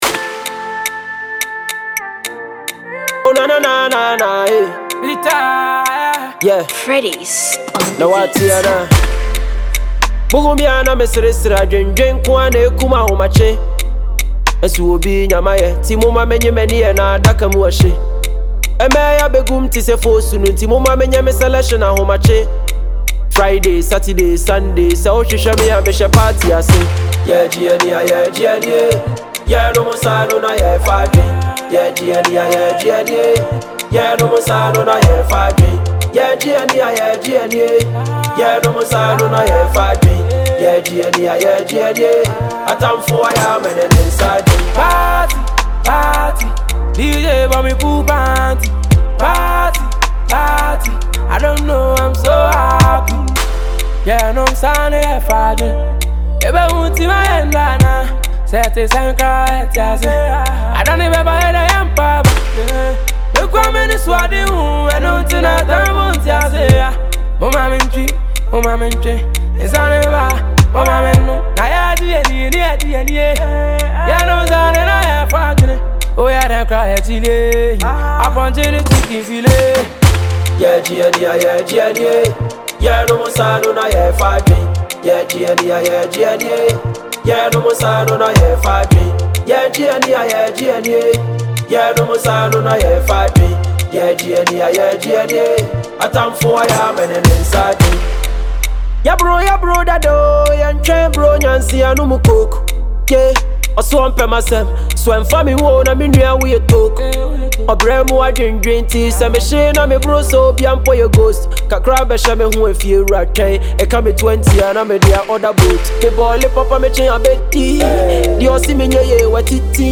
This is a banger all day.